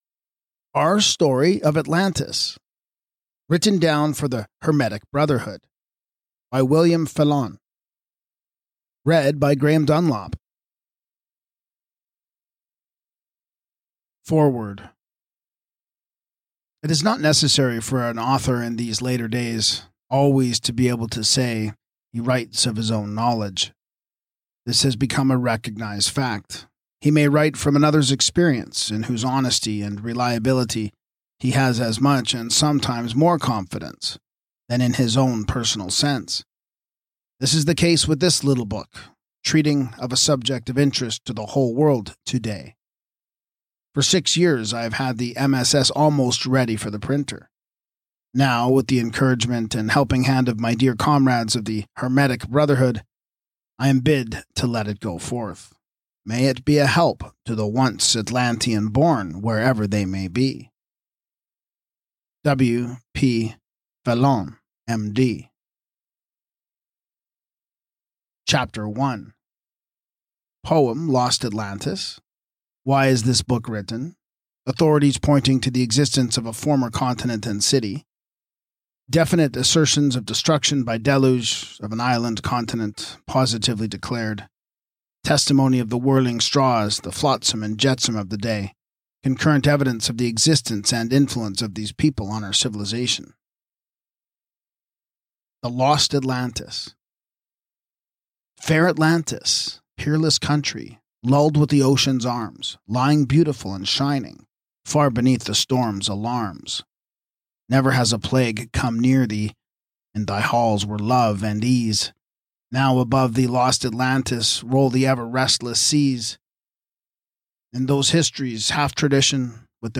Podcast (audiobooks): Play in new window | Download